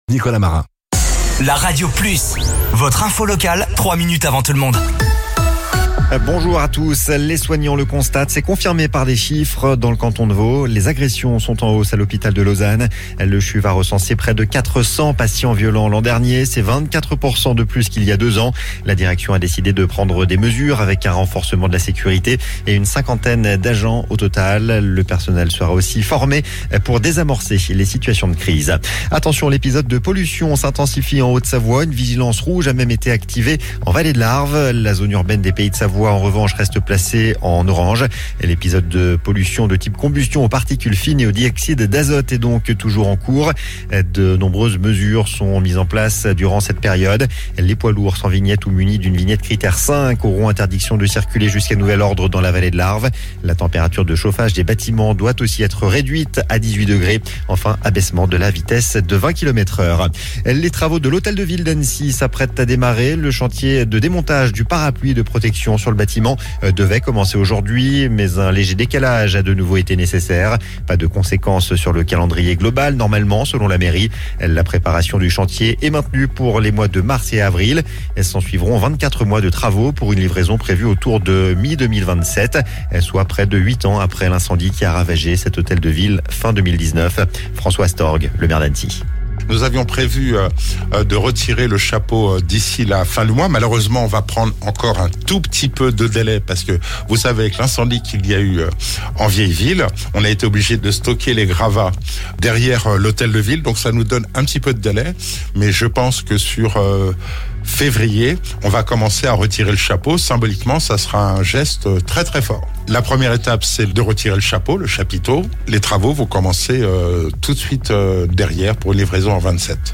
Flash Info
Votre flash info - votre journal d'information sur La Radio Plus